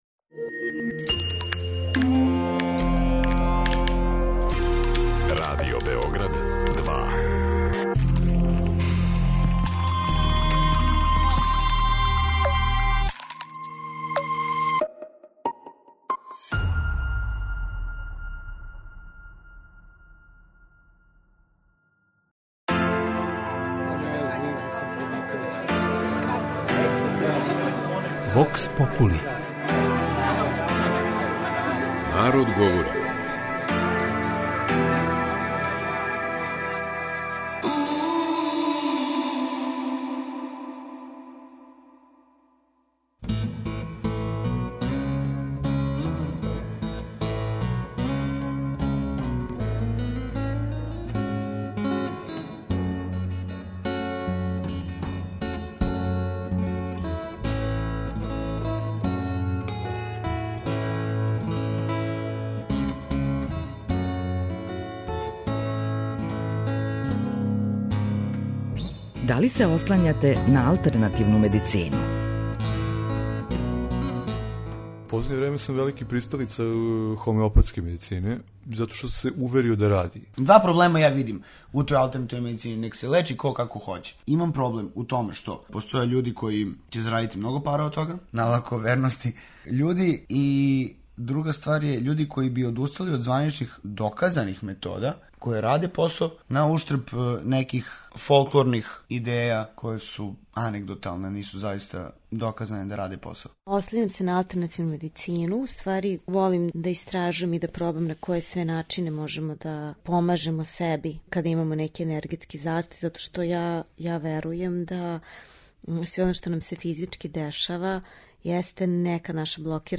Упркос званичној медицини, и даље није необично да се прибегне „народним лековима", а своје поверење многи пружају хомеопатији и акупунктури. У данашњој емисији питали смо грађане да ли се ослањају на алтернативну медицину.